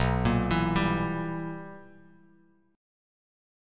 BM7sus4#5 Chord